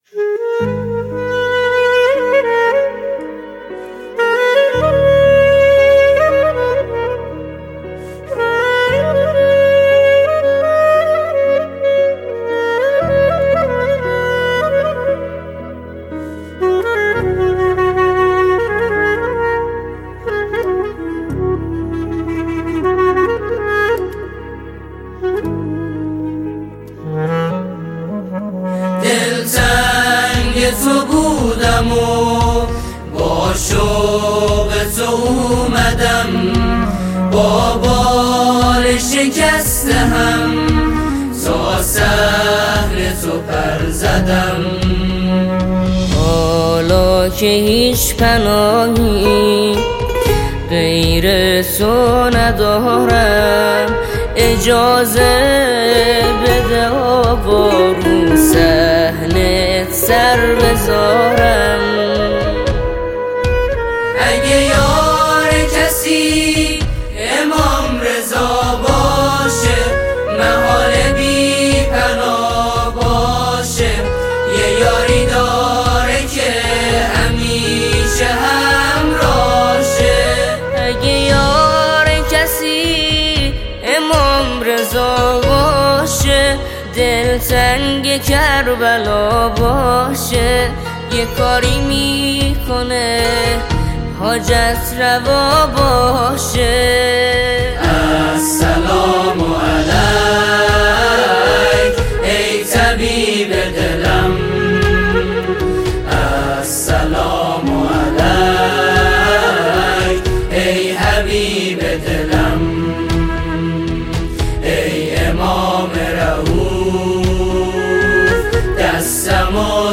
نماهنگ معنوی و احساسی
ژانر: سرود